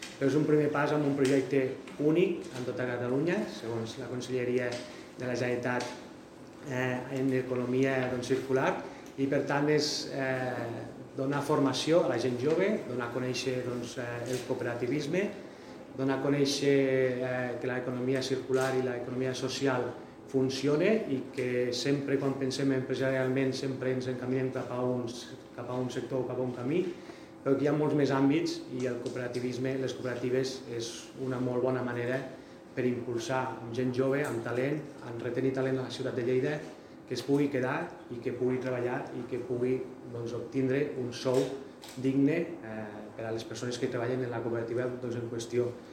tall-de-veu-del-regidor-ignasi-amor-sobre-la-2a-edicio-del-fem-coop